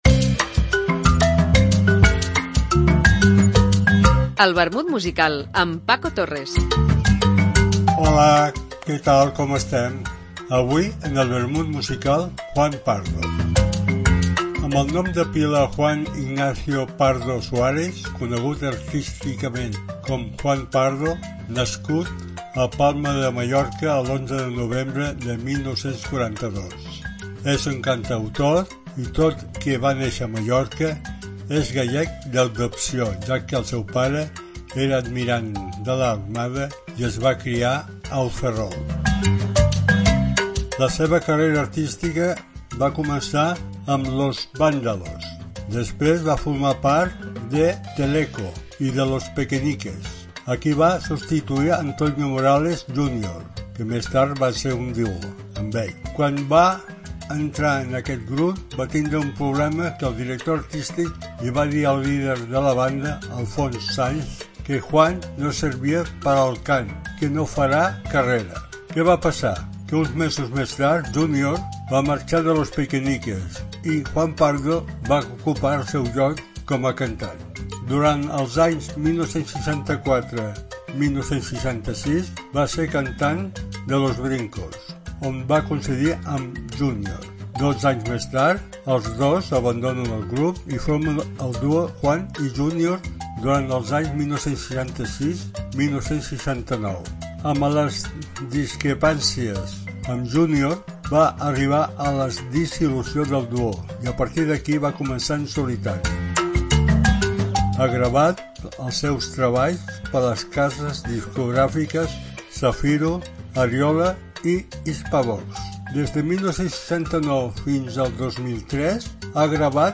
Una apunts biogràfics acompanyats per una cançó